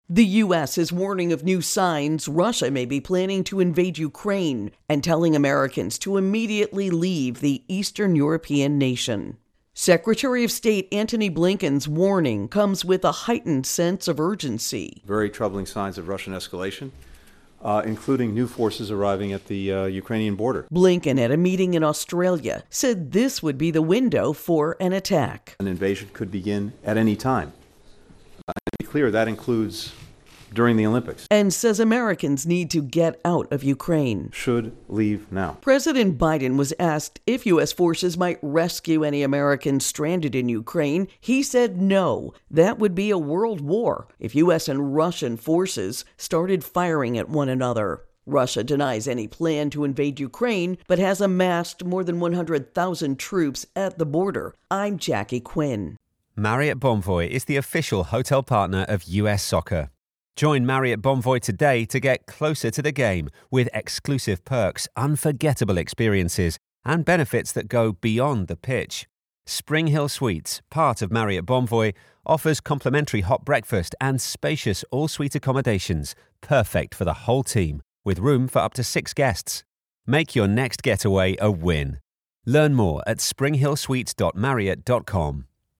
In remarks at a meeting with Indo-Pacific leaders, Secretary of State Antony Blinken says there's a risk that Russia may decide to invade Ukraine during the Beijing Olympics.